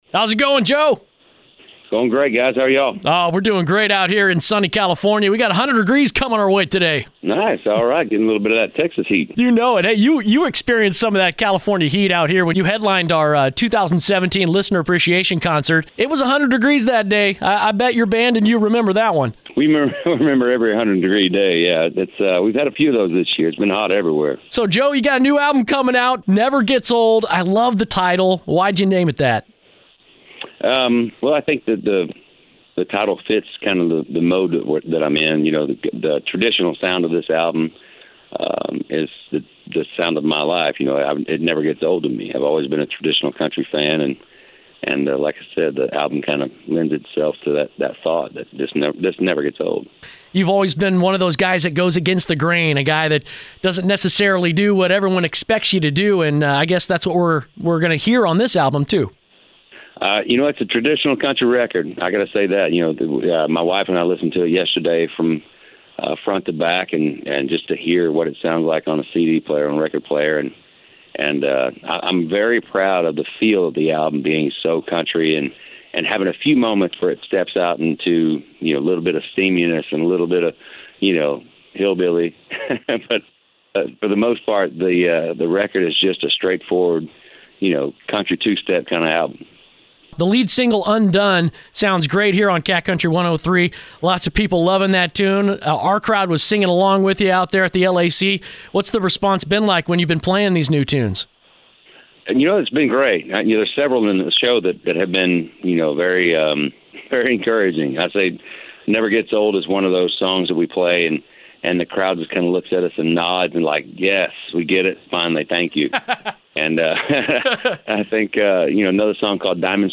Joe Nichols Interview!